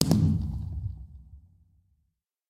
largeblast_far1.ogg